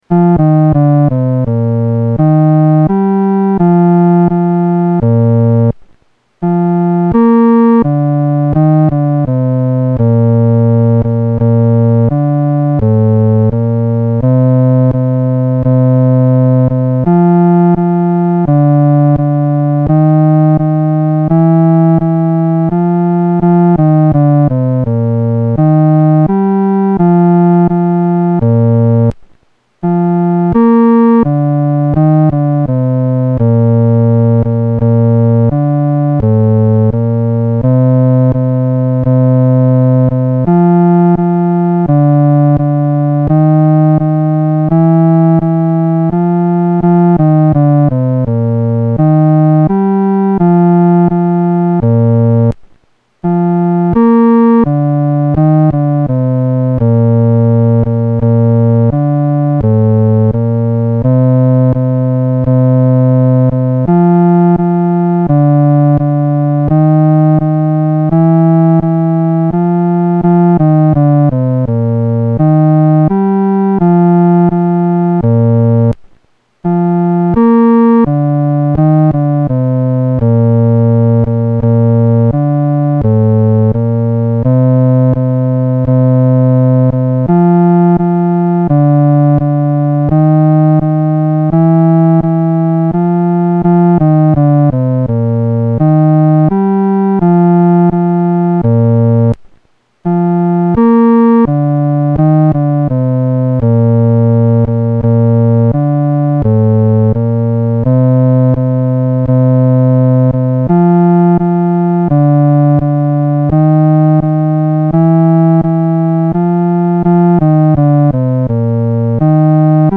伴奏
男低